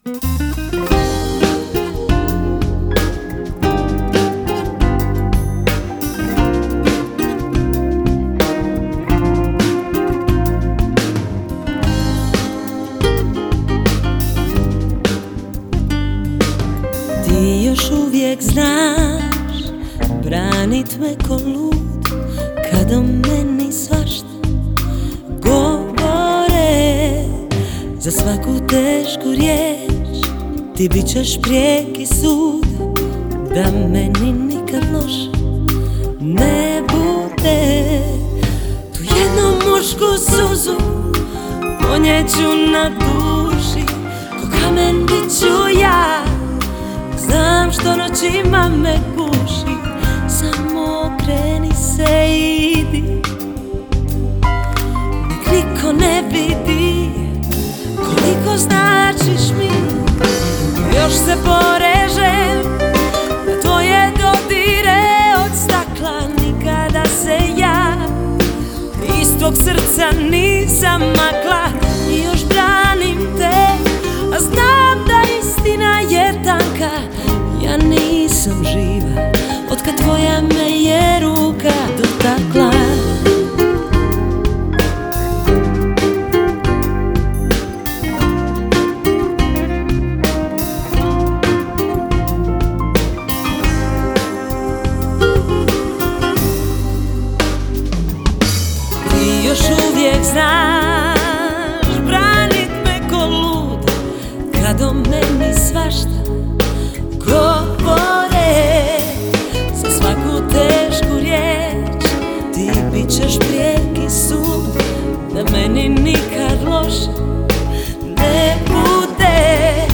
Популярная хорватская поп-певица.